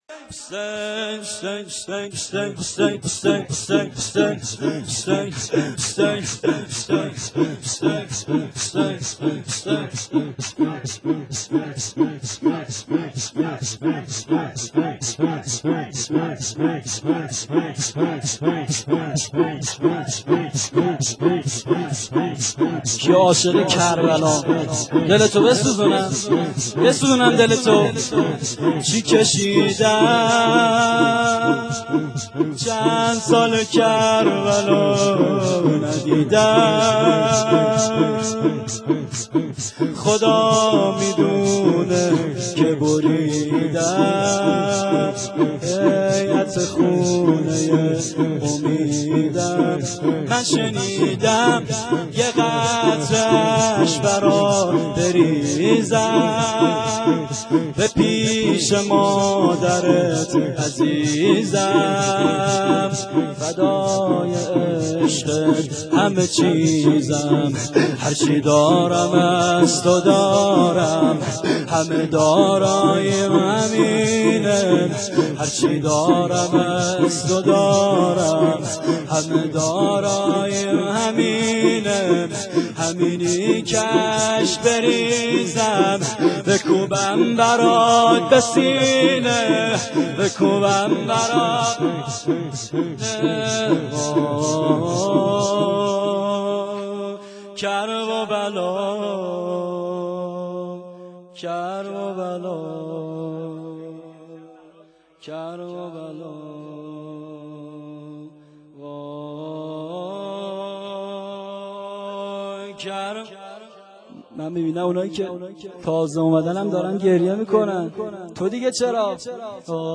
شور سوم